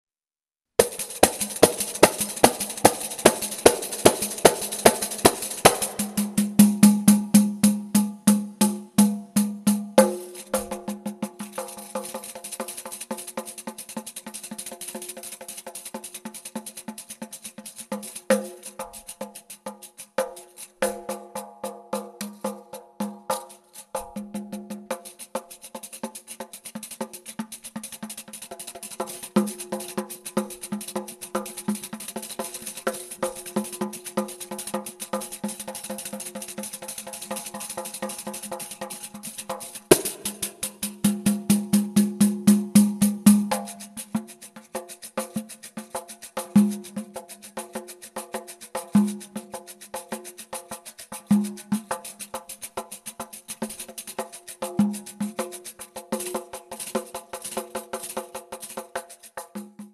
percussioni